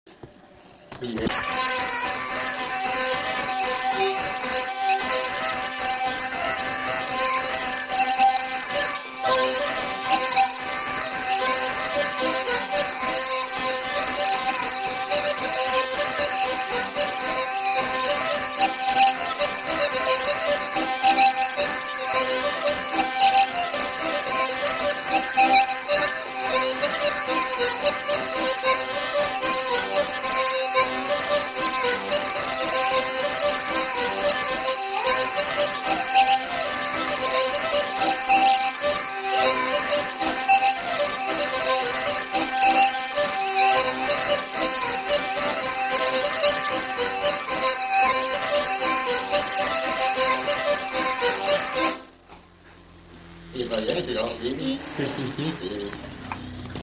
Aire culturelle : Gabardan
Lieu : Herré
Genre : morceau instrumental
Instrument de musique : vielle à roue ; accordéon diatonique
Danse : rondeau